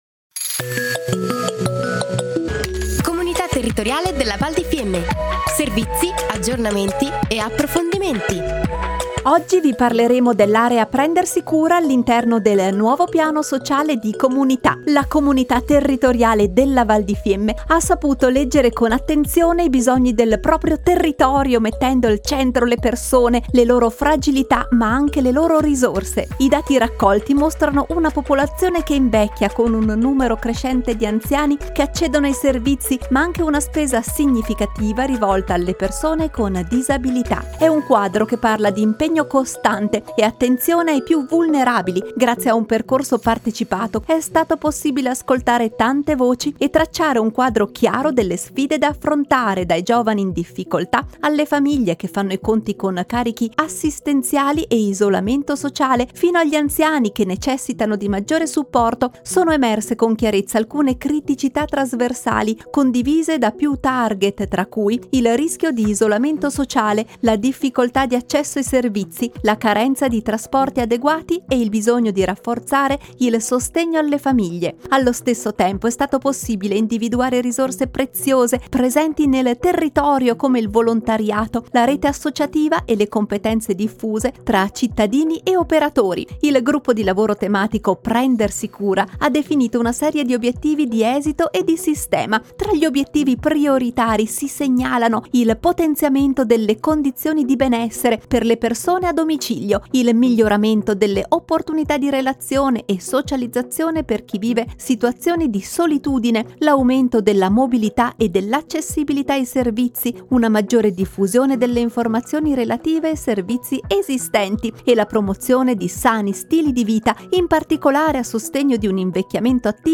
Puntata nr. 8 - Comunità Piano Sociale - Area Prendersi Cura / Anno 2025 / Interviste / La Comunità si presenta tramite Radio Fiemme / Aree Tematiche / Comunità Territoriale della Val di Fiemme - Comunità Territoriale della Val di Fiemme